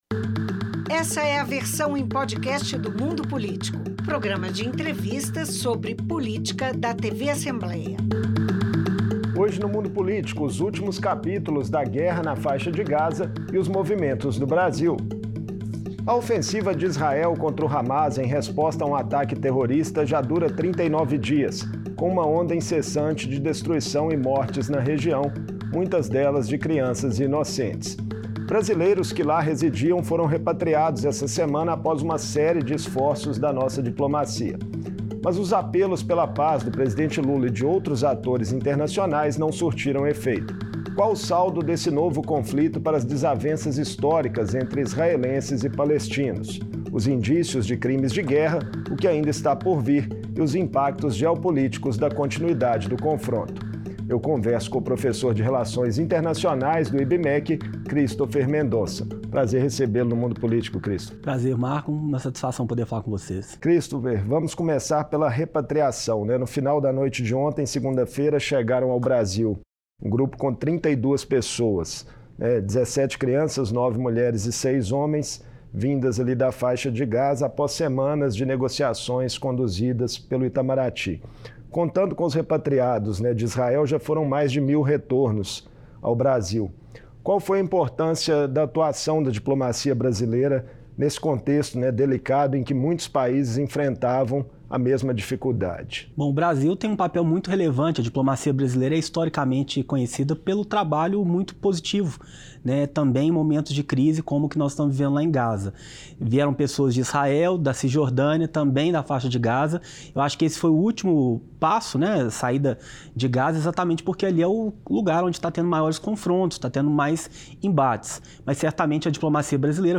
Em entrevista